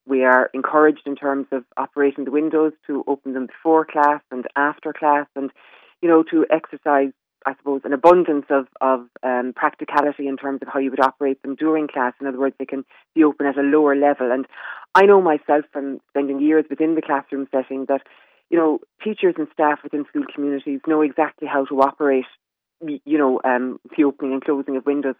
But Education Minister Norma Foley claims they have been “encouraged” by window ventilation despite these issues…